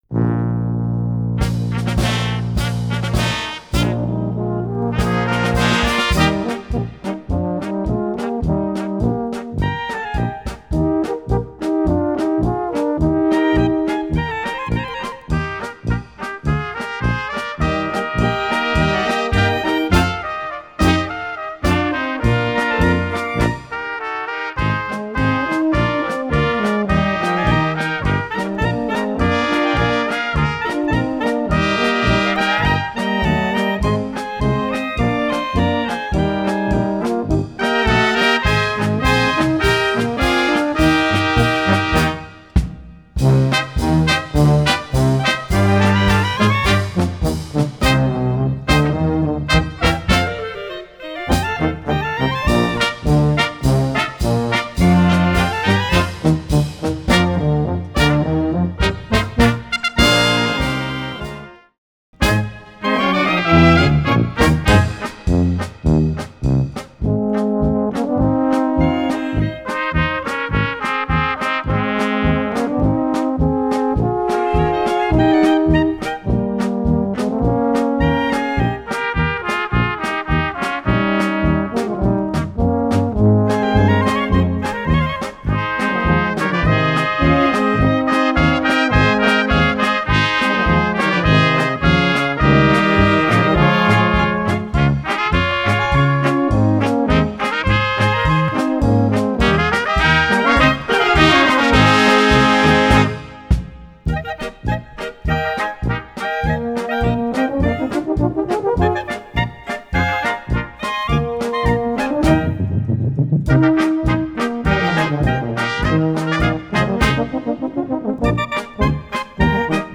für Blaskapelle